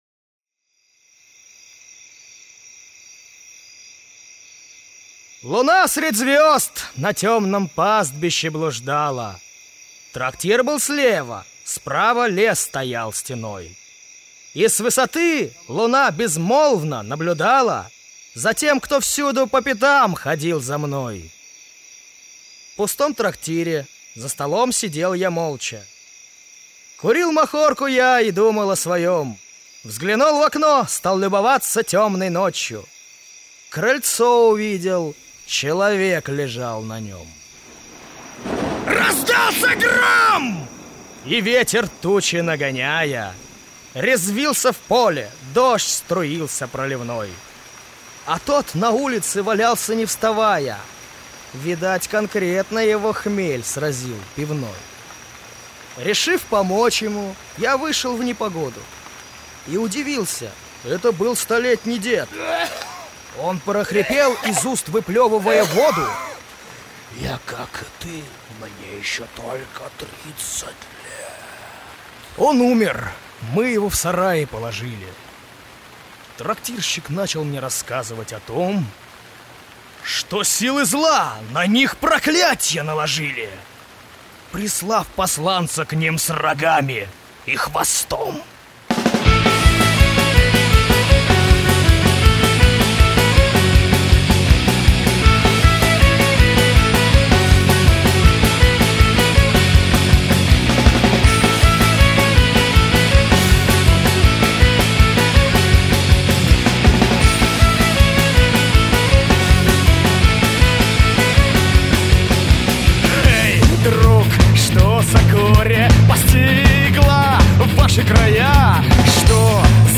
Главная » Онлайн Музыка » Рок